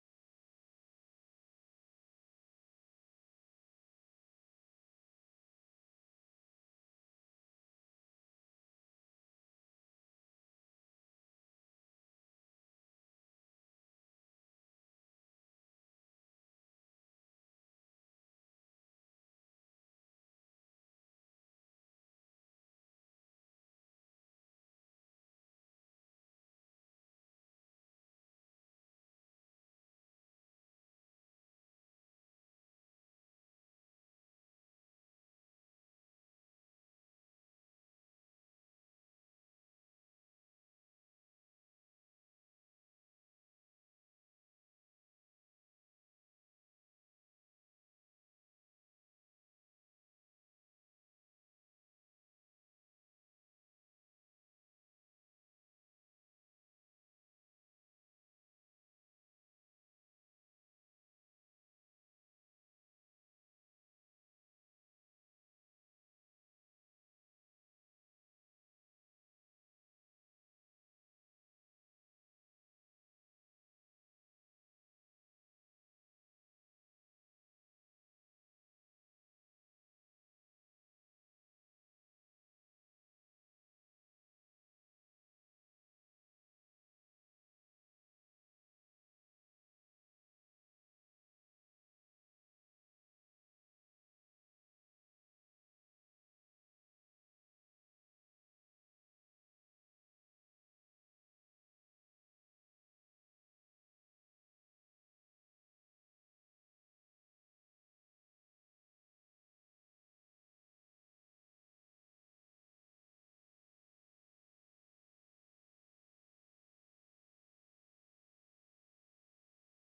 Sea-waves-beach-drone-video-_-Free-HD-Video-no-copyright-1.mp3